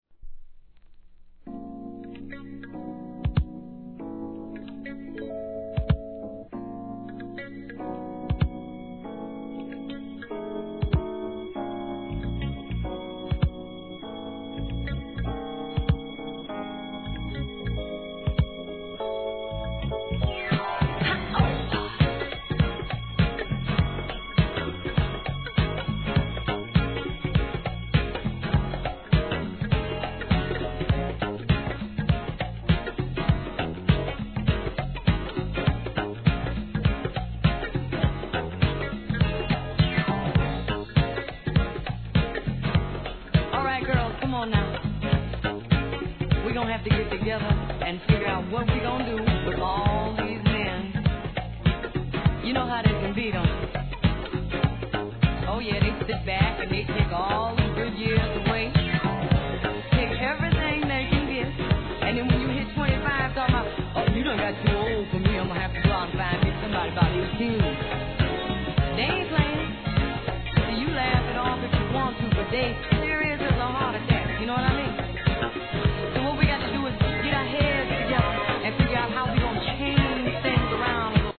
¥ 1,100 税込 関連カテゴリ SOUL/FUNK/etc...